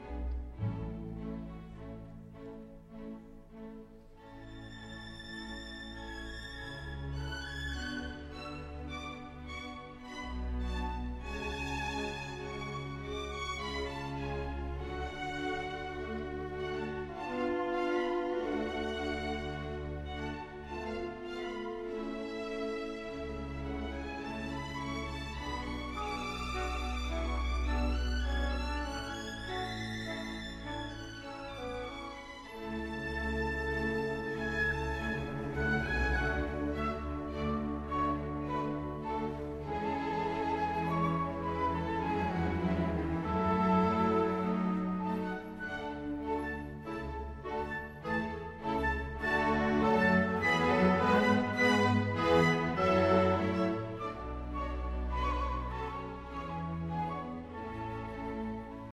Quarter note = 50